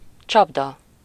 Ääntäminen
France: IPA: [pjɛʒ]